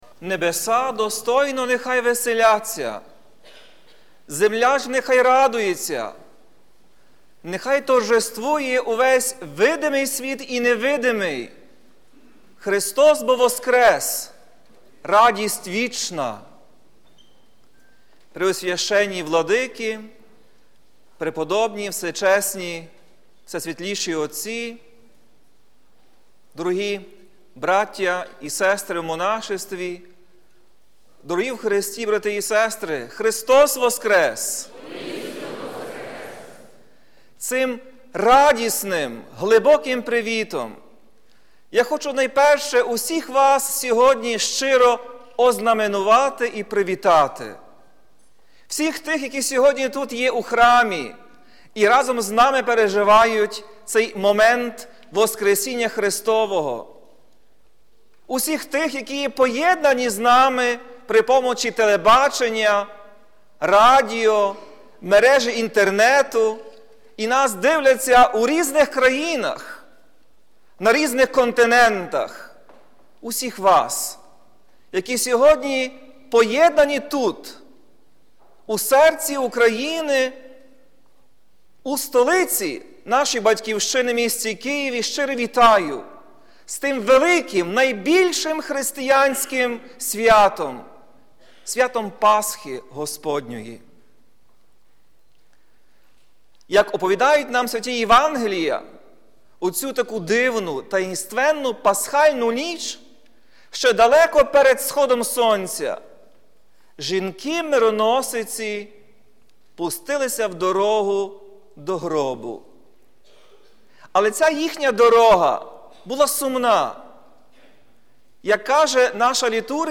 Проповідь Блаженнішого Святослава (Шевчука)